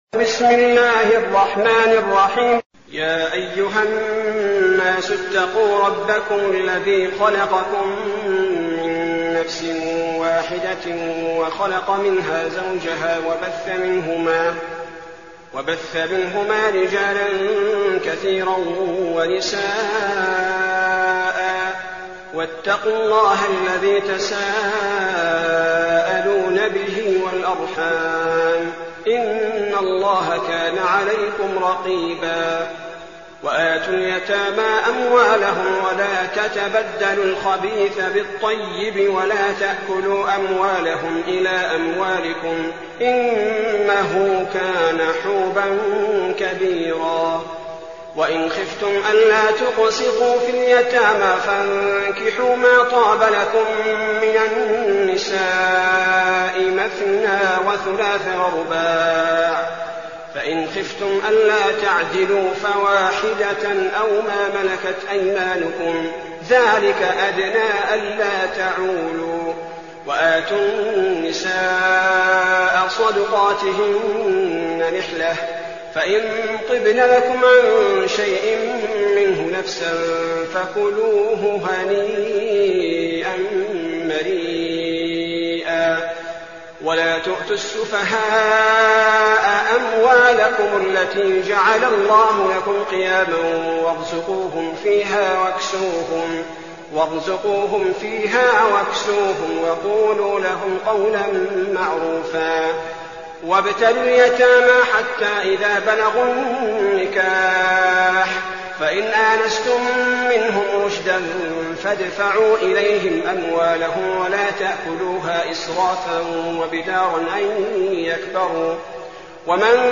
المكان: المسجد النبوي الشيخ: فضيلة الشيخ عبدالباري الثبيتي فضيلة الشيخ عبدالباري الثبيتي النساء